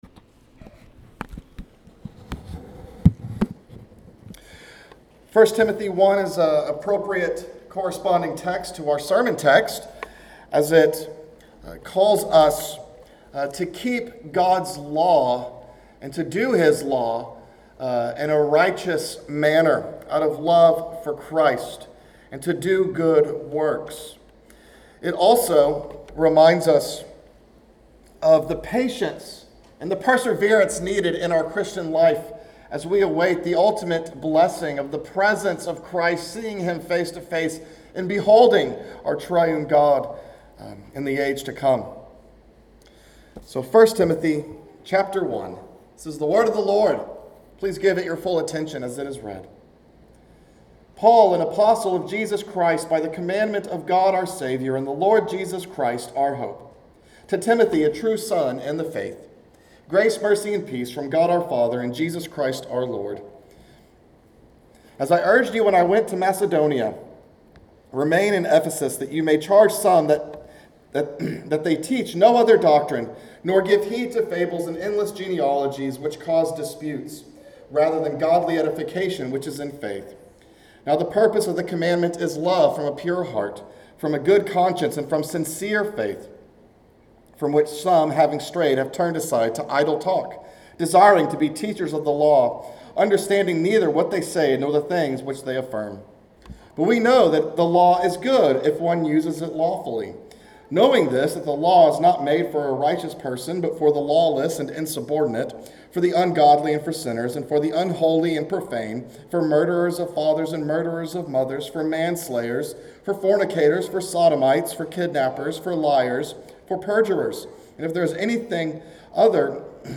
A message from the series "Haggai."